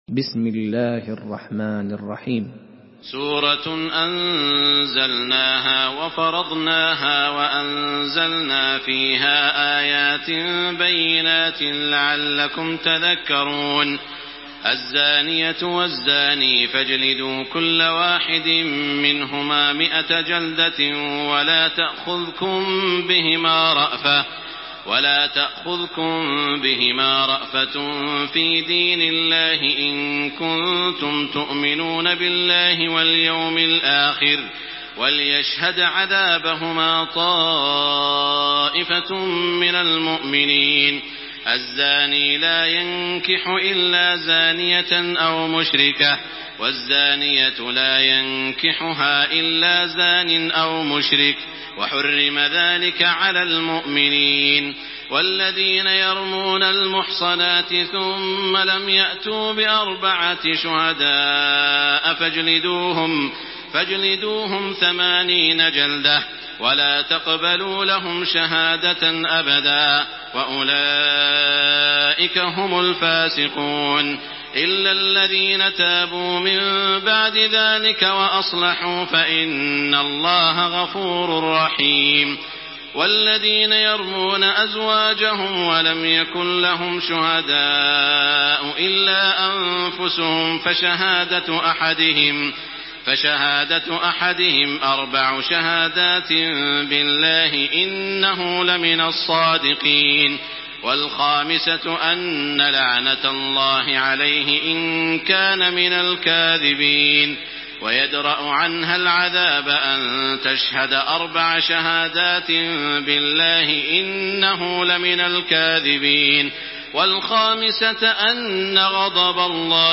Surah An-Nur MP3 by Makkah Taraweeh 1428 in Hafs An Asim narration.
Murattal